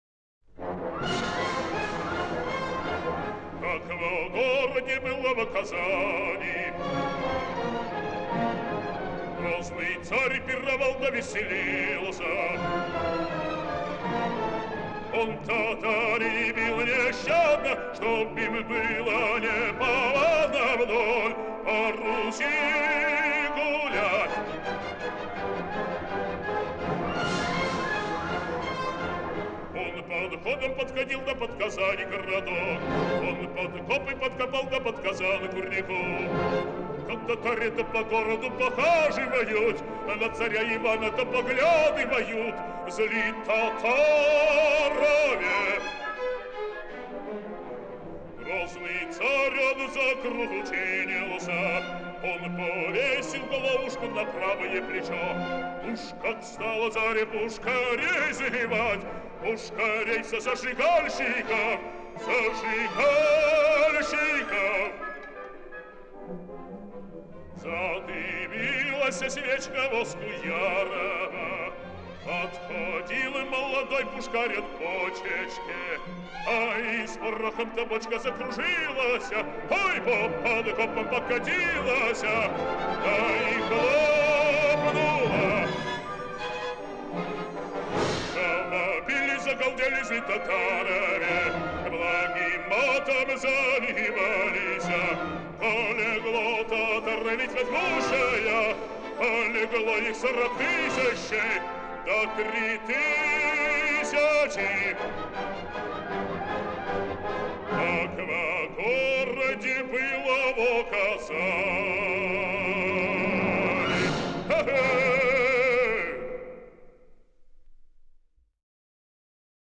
Борис Гмыря, Орк. ГАБТ СССР, дир. В.Небольсин - Песня Варлаама (М.П.Мусоргский. Борис Годунов)
boris-gmyirya,-ork.-gabt-sssr,-dir.-v.nebolsin---pesnya-varlaama-(m.p.musorgskiy.-boris-godunov).mp3